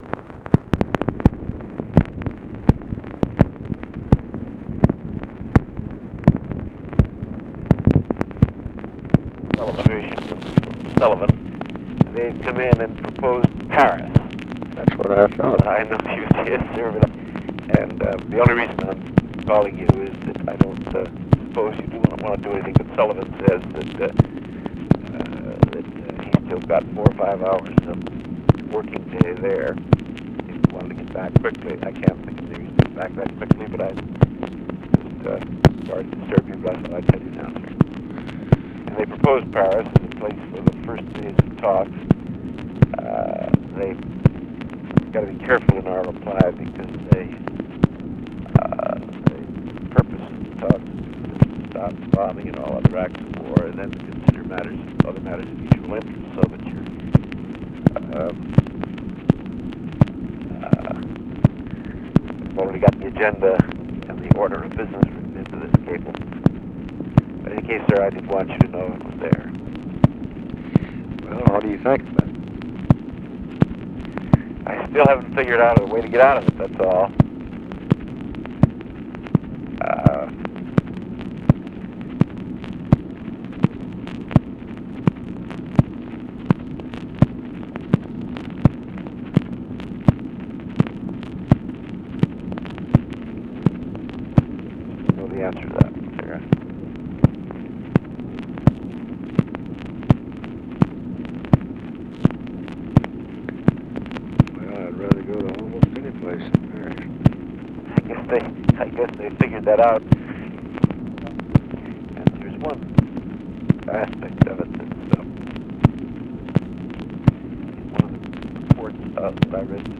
Conversation with WALT ROSTOW, May 3, 1968
Secret White House Tapes